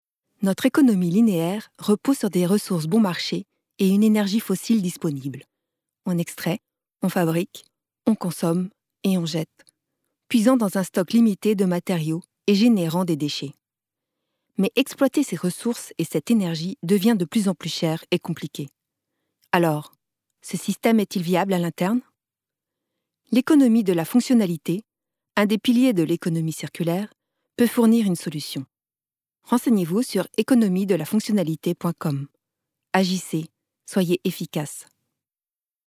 Institutionnel
Voix off
5 - 53 ans - Mezzo-soprano